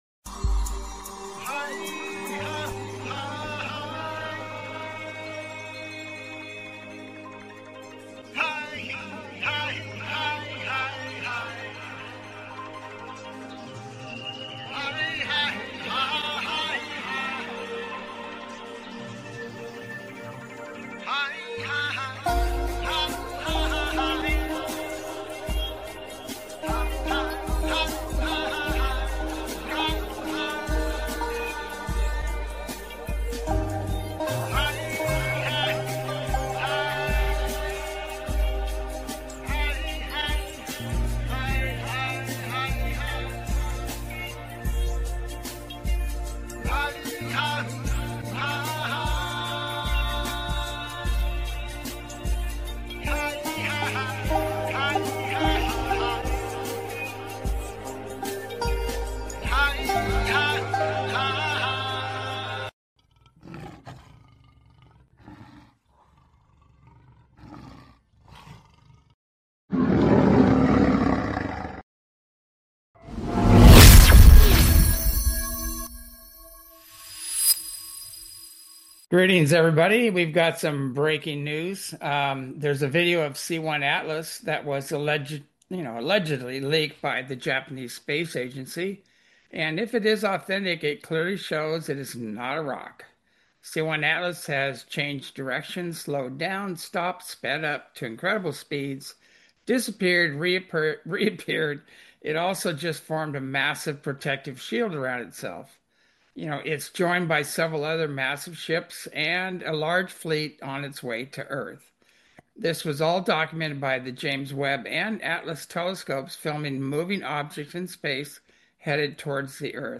Talk Show Episode, Audio Podcast, As You Wish Talk Radio and C1 Atlas, Swarms Of Ships, Contact Before November 7th, Real Disclosure Coming on , show guests , about C1 Atlas,Swarms Of Ships,Contact Before November 7th,Real Disclosure Coming, categorized as Earth & Space,News,Paranormal,UFOs,Philosophy,Politics & Government,Science,Spiritual,Theory & Conspiracy